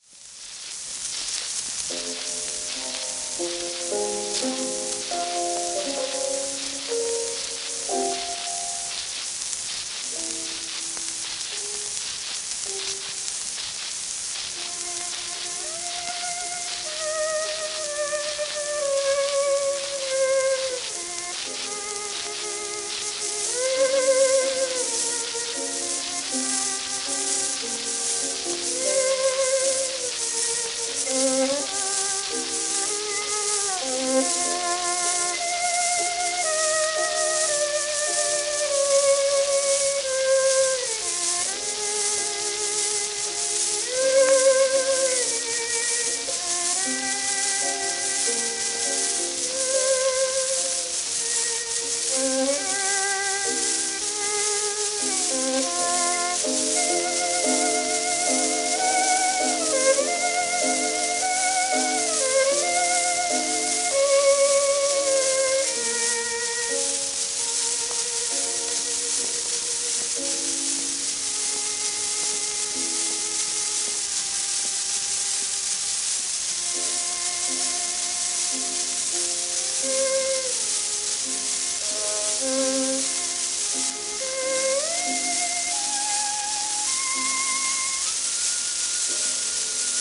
1913年録音
旧 旧吹込みの略、電気録音以前の機械式録音盤（ラッパ吹込み）